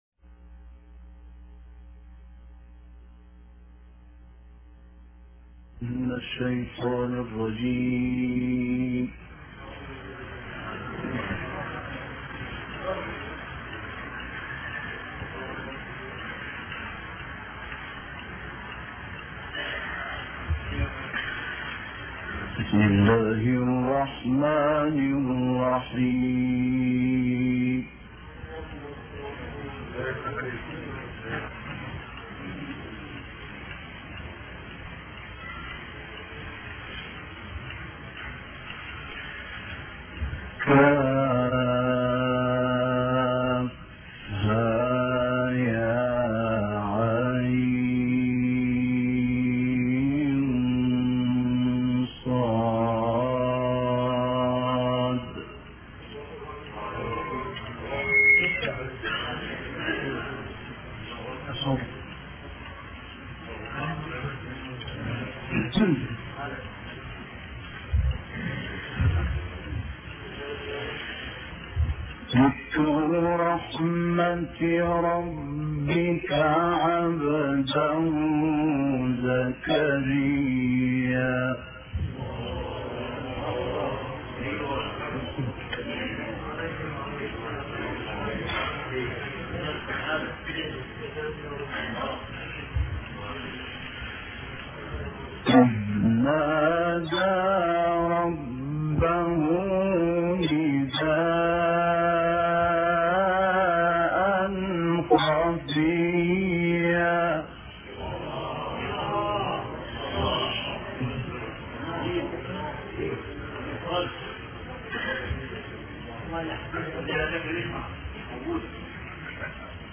تلاوت آیاتی از سوره مریم توسط مرحوم شحات محمد انور